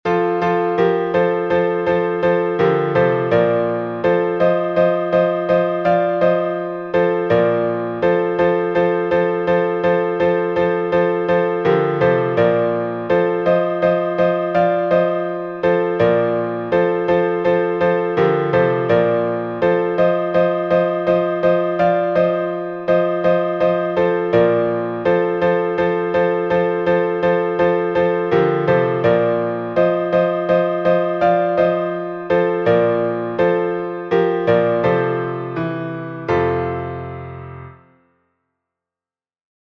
Сокращённый греческий распев, глас 1